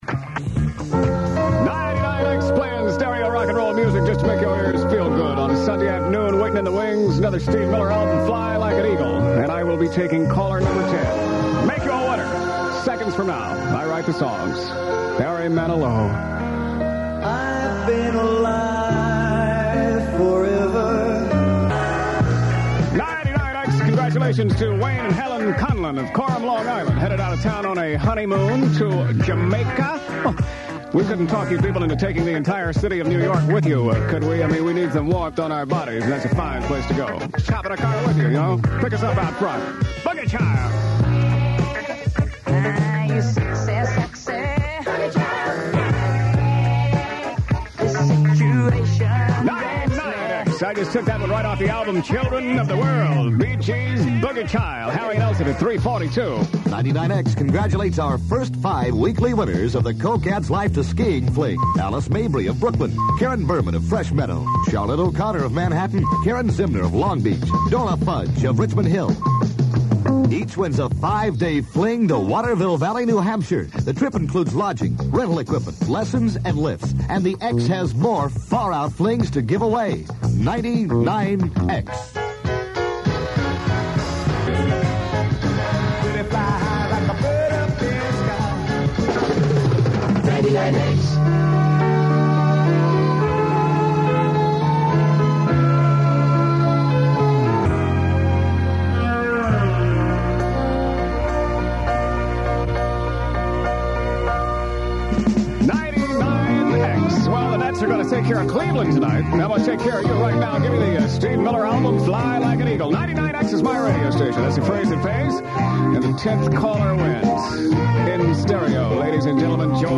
99X Airchecks